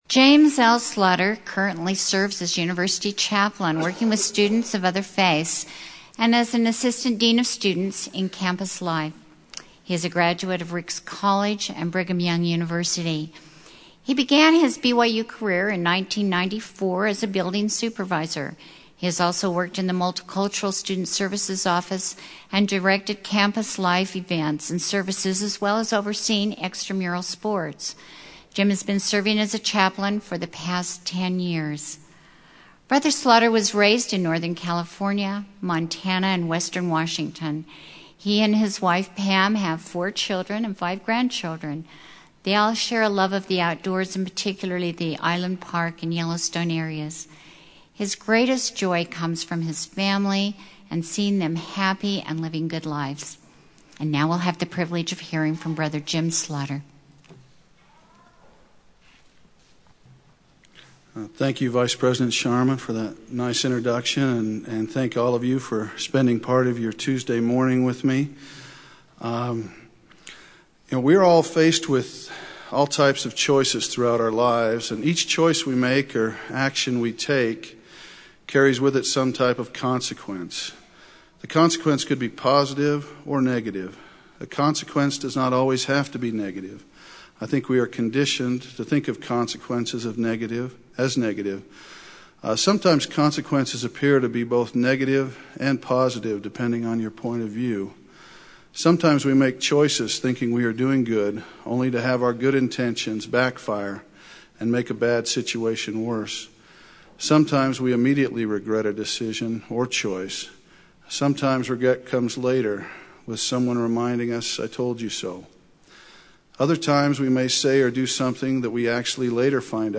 Devotional